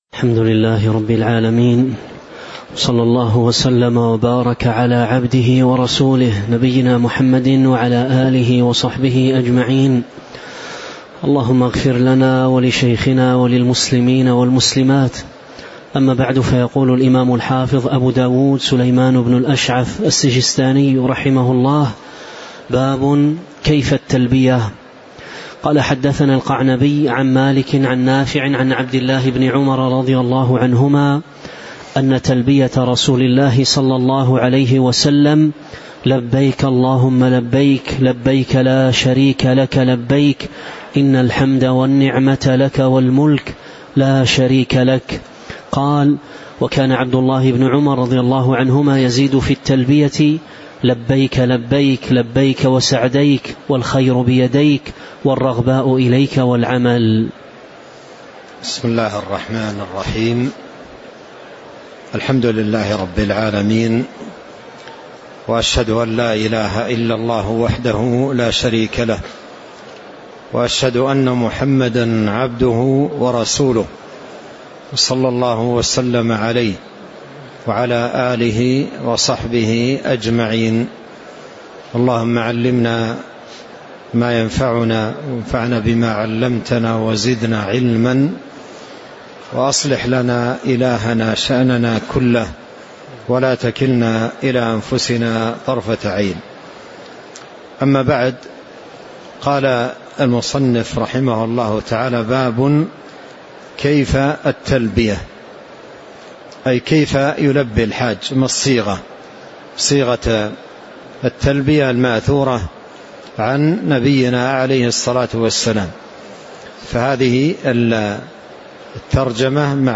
تاريخ النشر ٣ ذو الحجة ١٤٤٦ المكان: المسجد النبوي الشيخ